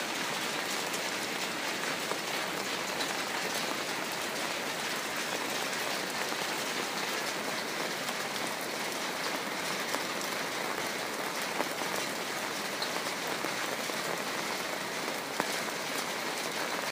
Утренний дождь и прогулка под прикрытием зонтика